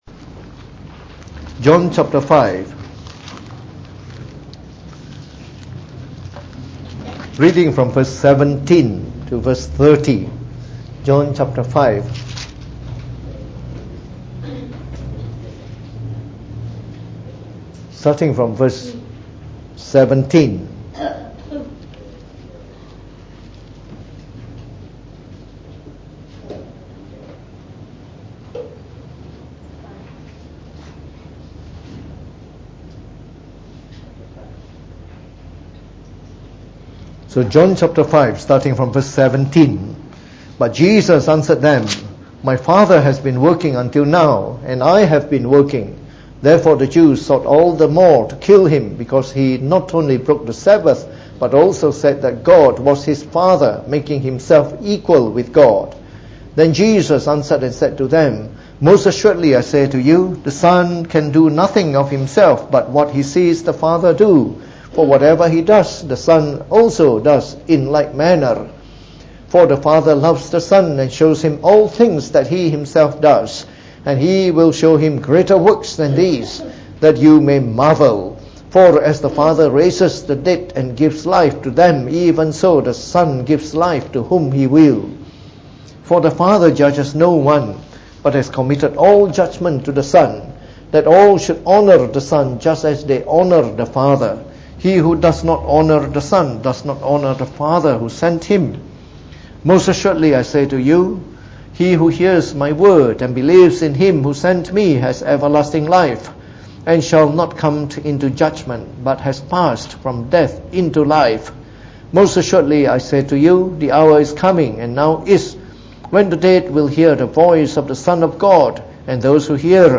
Preached on the 30th September 2018.